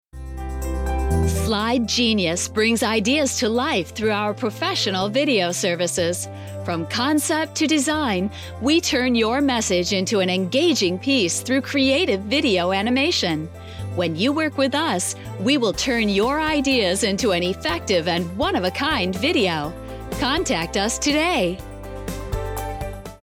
Voice over examples
Female Voice 5